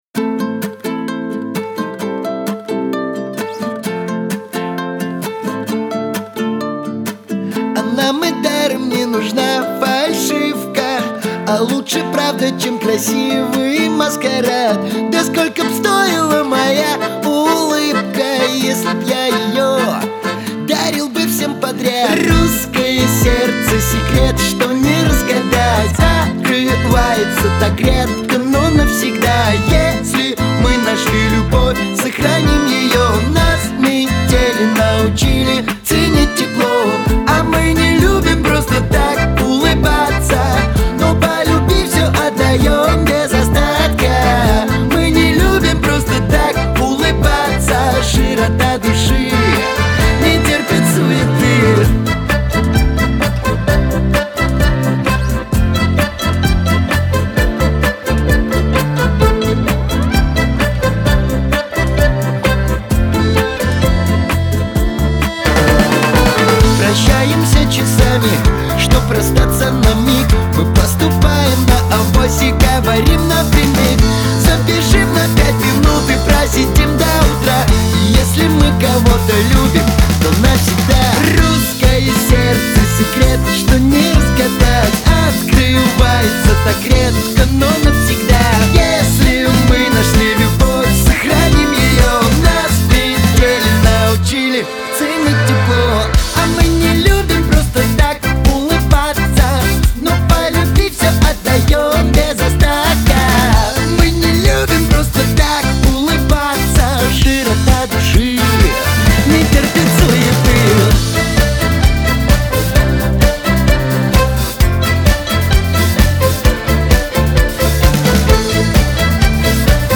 эстрада
Лирика
pop